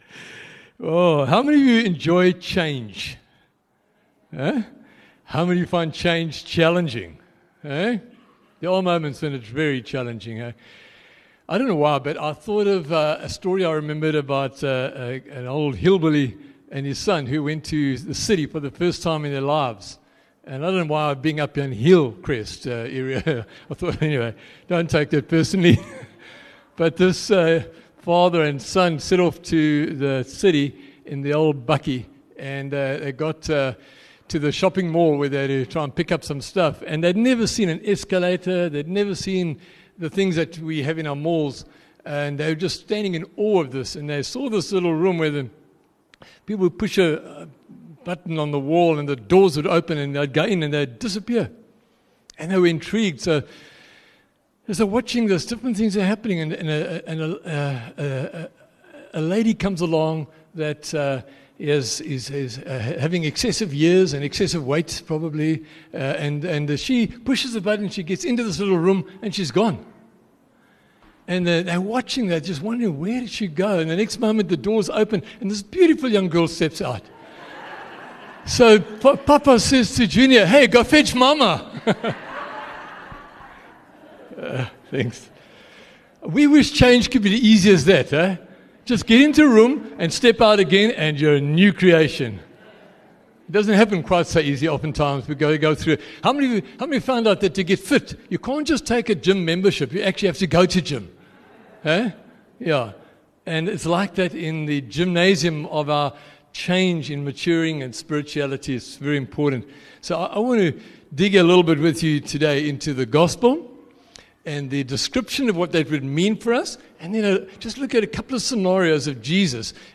4 Feb Family Service
View Promo Continue JacPod Install Upper Highway Vineyard Sunday messages 4 Feb Family Service 41 MIN Download